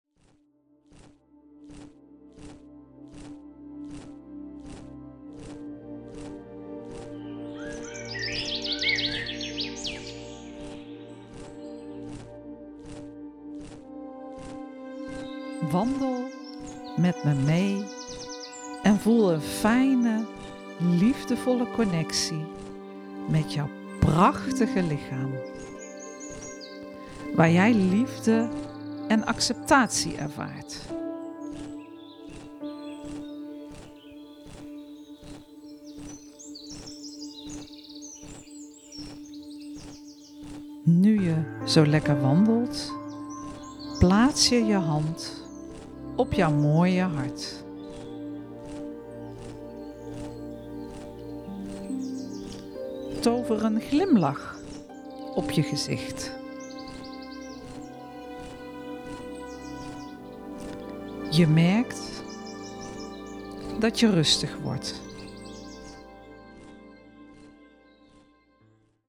Wandelmeditatie (18 minuten)
wandelmeditatie-teaser.mp3